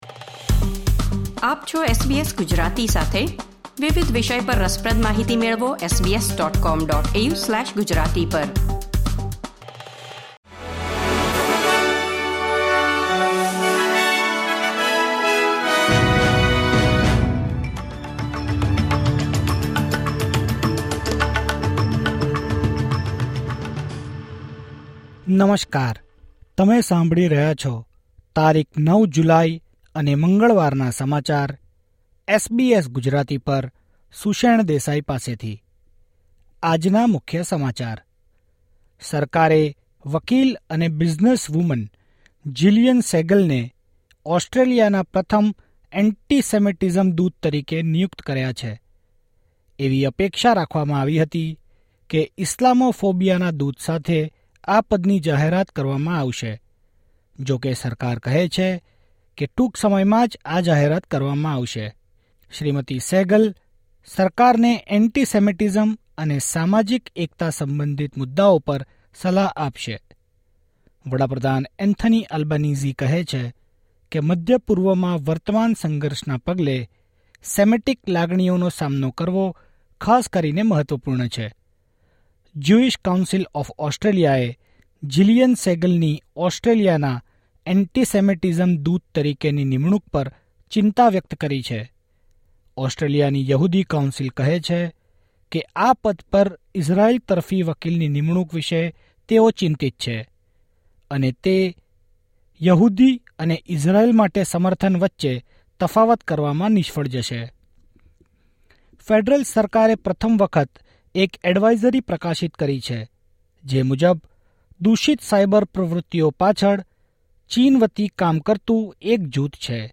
SBS Gujarati News Bulletin 9 July 2024